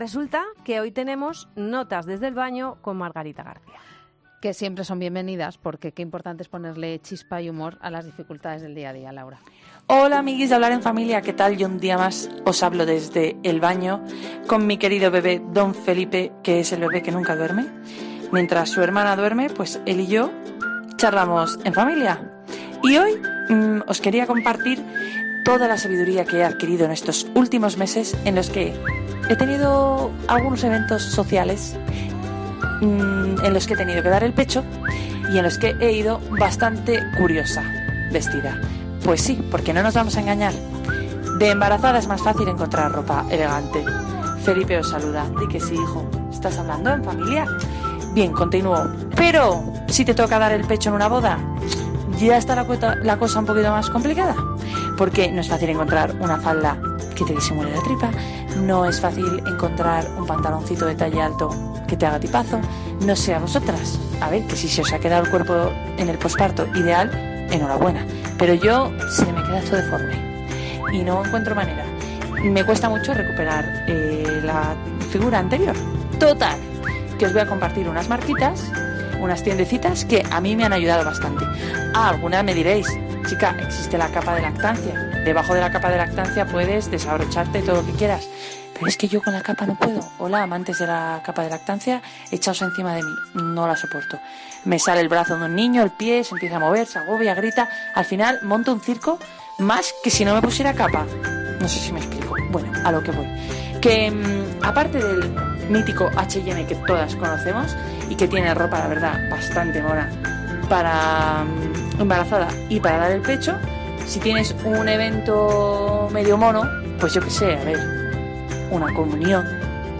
No te pierdas con su humor característico a esta madre que nos manda notas de voz desde el baño donde una bimadre con niños muy pequeños siempre busca intimidad hasta que son mayores y llegan al picaporte y entran estés haciendo lo que estés haciendo...